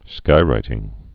(skīrītĭng)